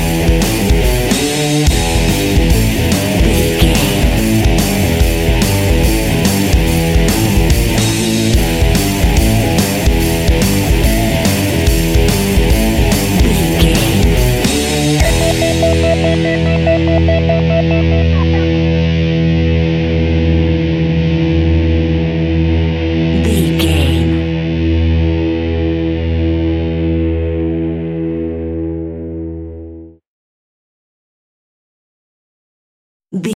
Epic / Action
Aeolian/Minor
heavy metal
instrumentals
Rock Bass
heavy drums
distorted guitars
hammond organ